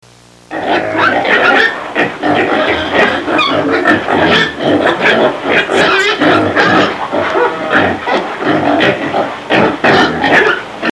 Pig sound
Category: Animals/Nature   Right: Personal